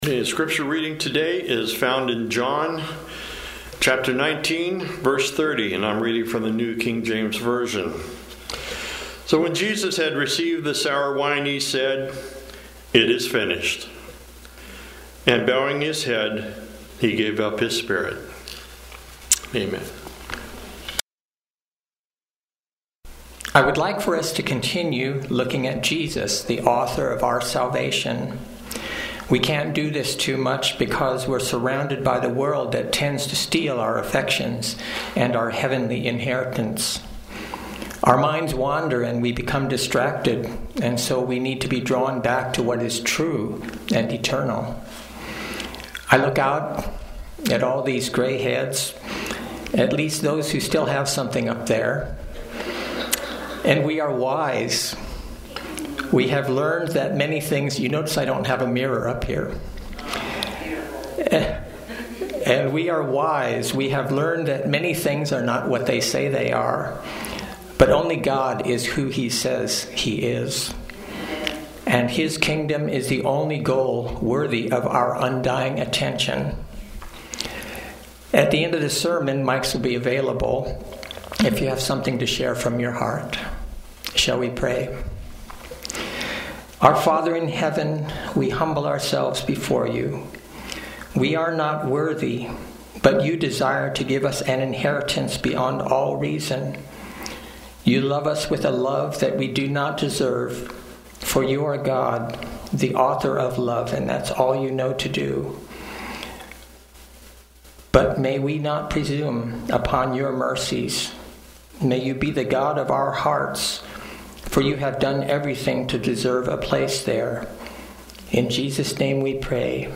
Sermons and Talks 2024